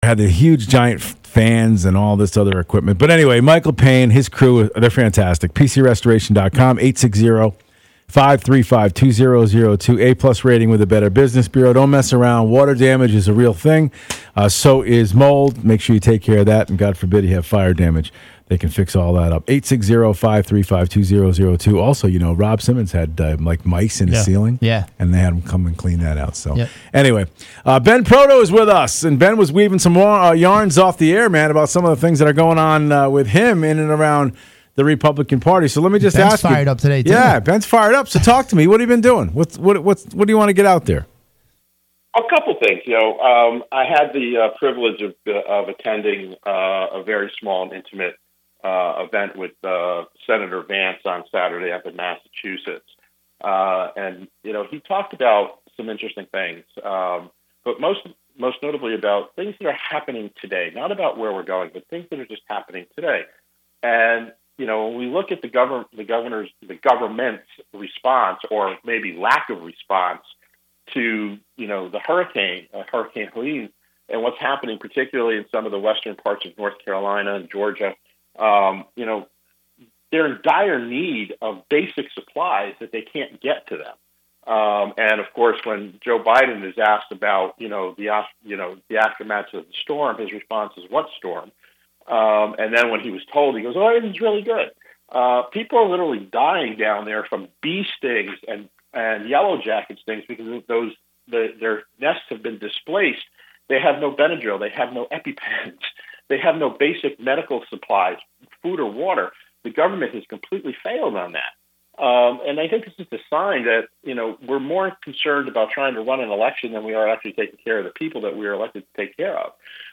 on the radio